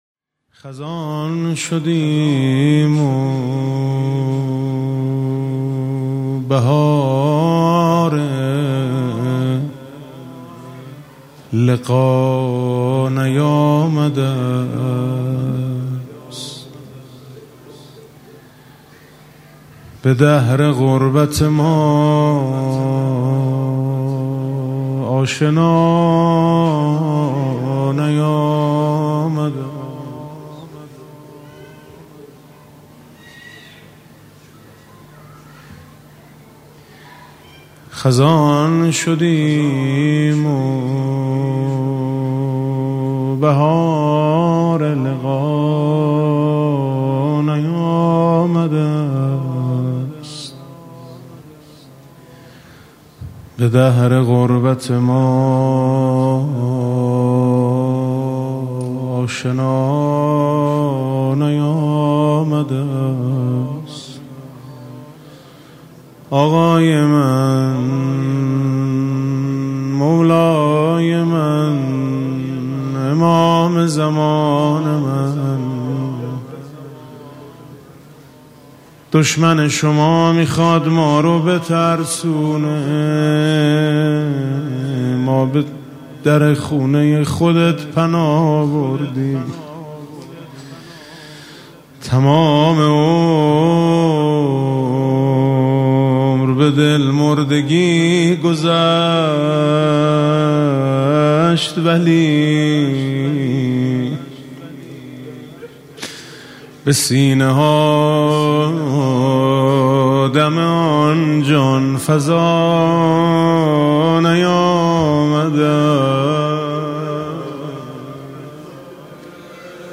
[آستان مقدس امامزاده قاضي الصابر (ع)]
مناسبت: قرائت مناجات شعبانیه
با نوای: حاج میثم مطیعی